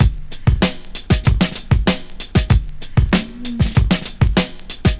Tuned drums (C# key) Free sound effects and audio clips
• 96 Bpm 00s Breakbeat Sample C# Key.wav
Free drum beat - kick tuned to the C# note. Loudest frequency: 1398Hz
96-bpm-00s-breakbeat-sample-c-sharp-key-i2p.wav